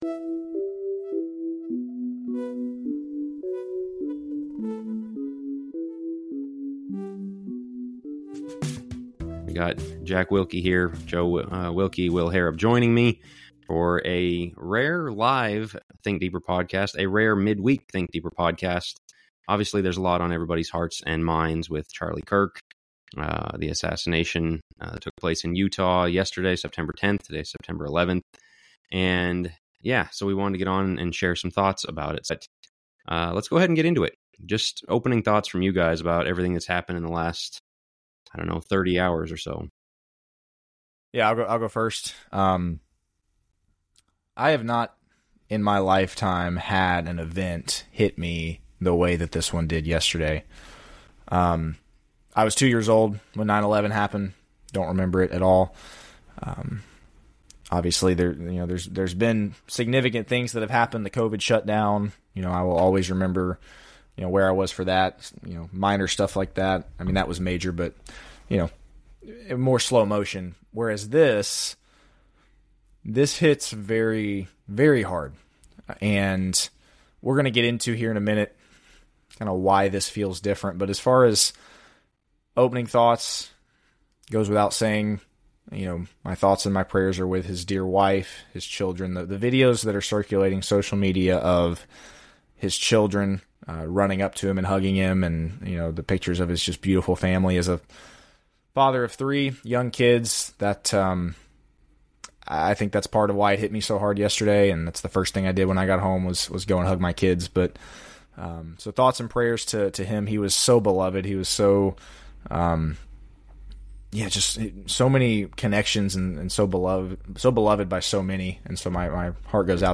The TD crew discusses the assassination of Charlie Kirk, Kirk's work, and the church's response Chapters: 00:00 - Intro and why this event felt different09:32 - Rejecting Bothsidesism18:46 - The courage to point out what's really happening22:55 - Rejecting Aboveitallism35:32 - The place of righteous anger44:50 - Can we stomach difficult realities?52:00 - What must be done